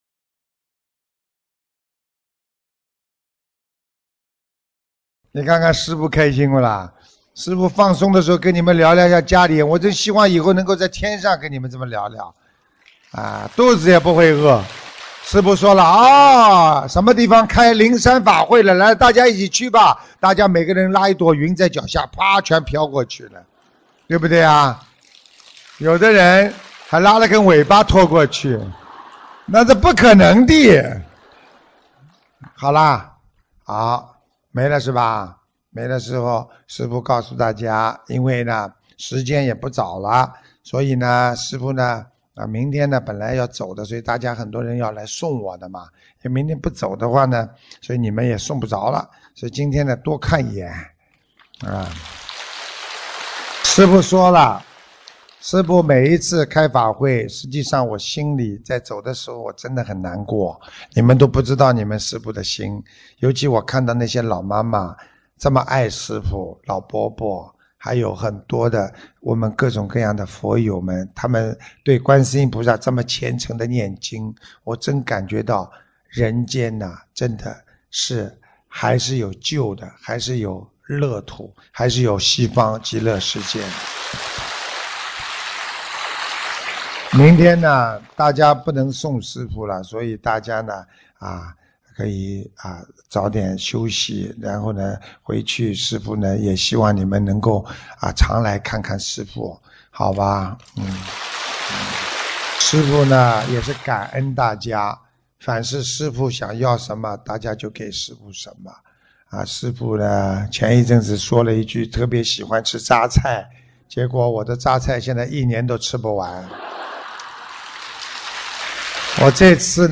2015年4月12日新加坡世界佛友见面会结束语-经典开示节选 - 法会结束语 - 心如菩提 - Powered by Discuz!